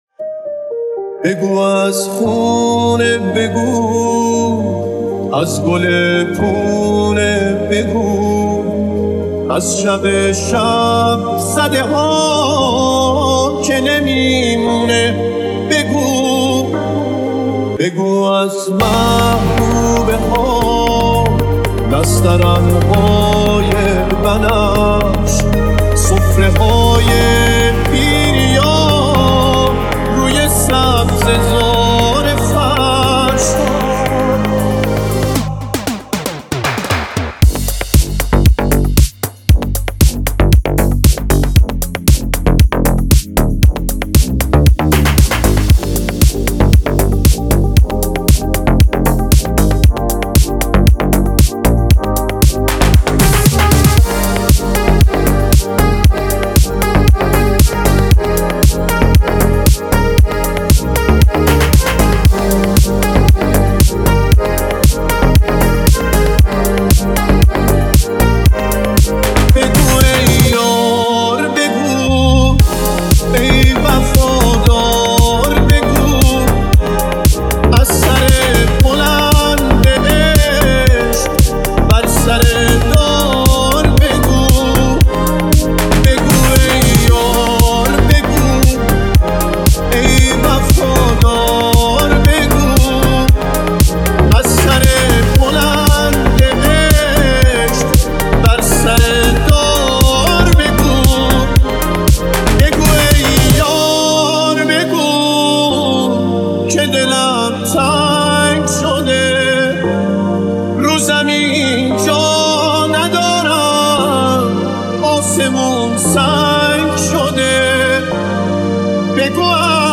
دانلود ریمیکس پاپ